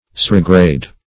Search Result for " cirrigrade" : The Collaborative International Dictionary of English v.0.48: Cirrigrade \Cir"ri*grade\, a. [Cirrus + L. gradi to walk.]
cirrigrade.mp3